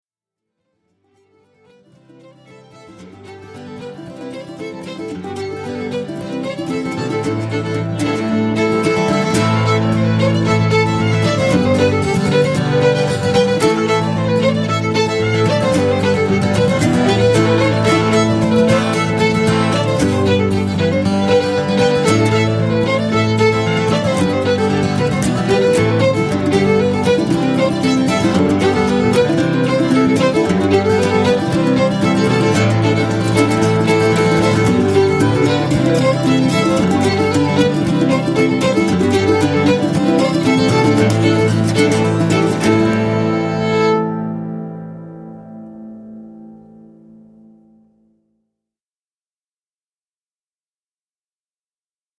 reel
fiddle & guitar duo
Scots-Irish & Americana